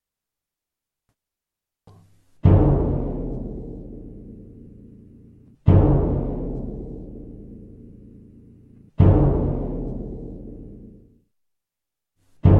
bombo.mp3